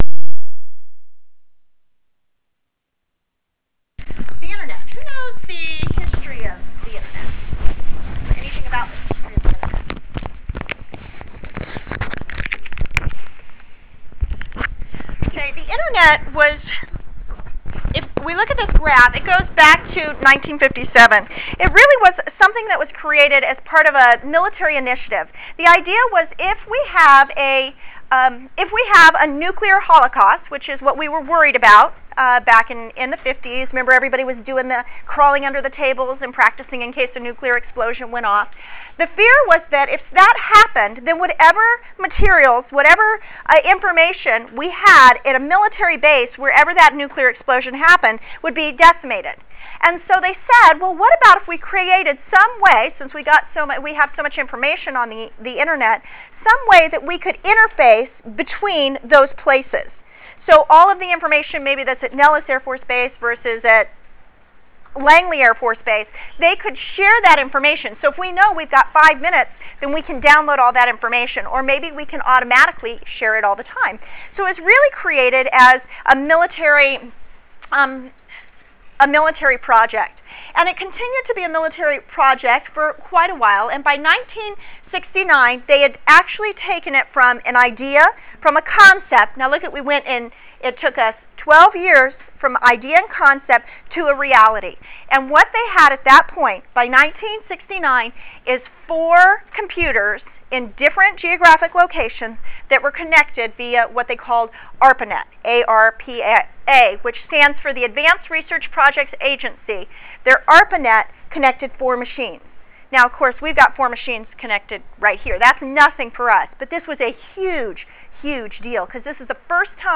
Review the lesson plan , lecture slides , and lecture audio .